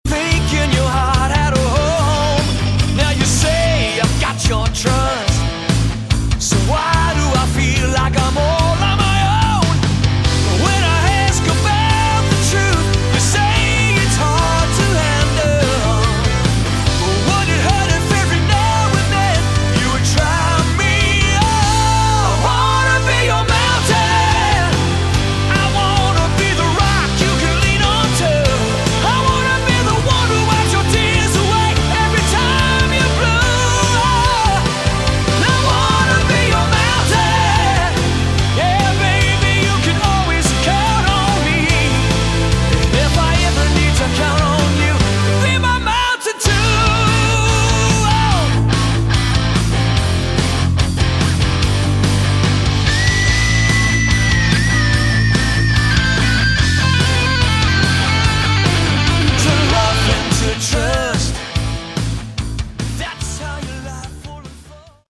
Category: AOR
guitars
lead and backing vocals
bass
drums
keyboards
The rhythm of most of the 10 works is fast.